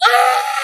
Zach Hadel Bird Scream (isolated)